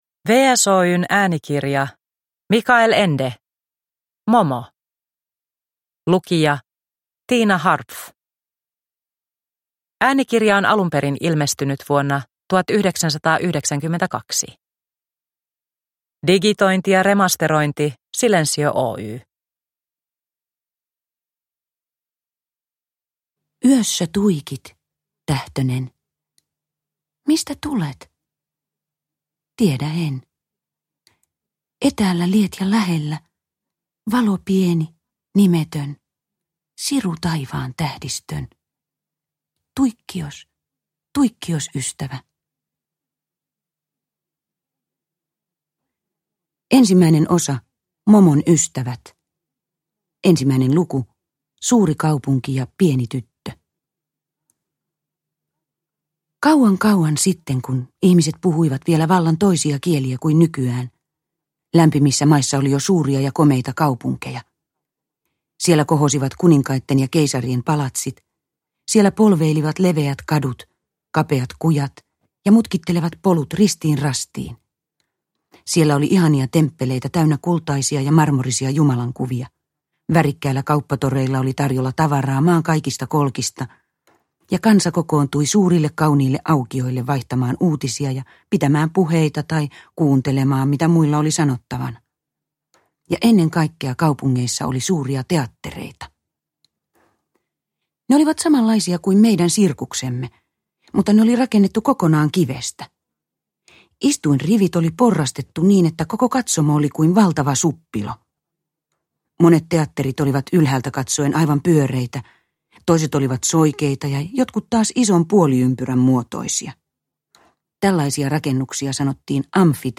Momo – Ljudbok